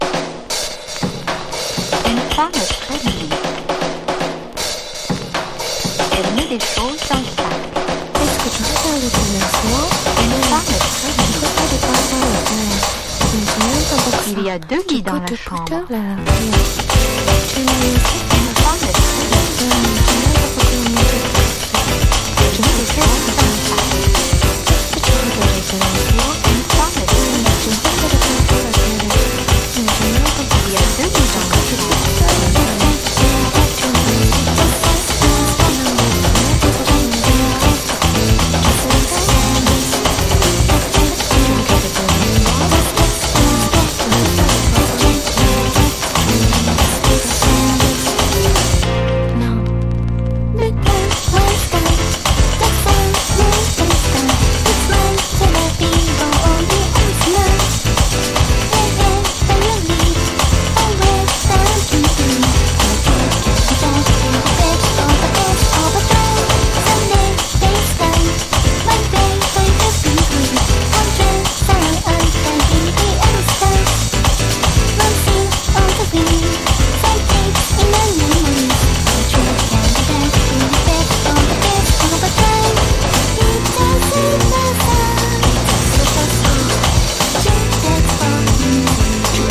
ロリータ・スキャットが最高なA1他、オシャレでキュートな楽曲全4曲収録！
# POP